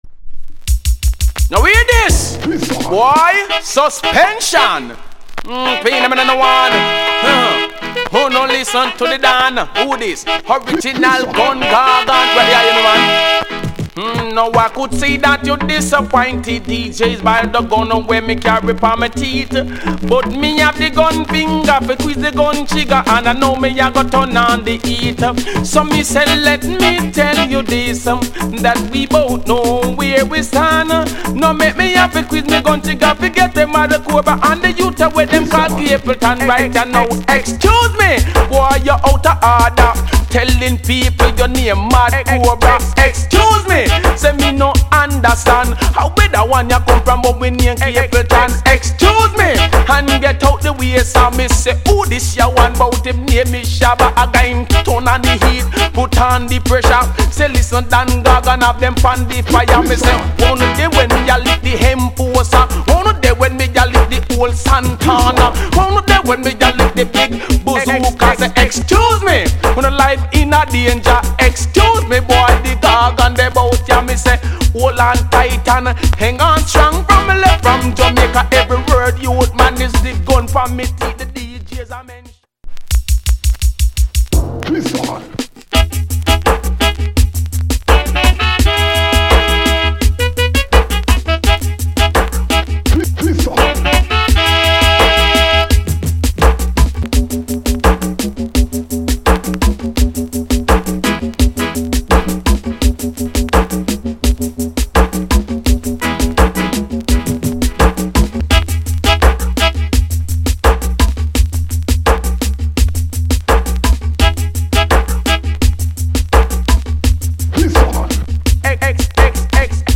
DJ Tune!
riddim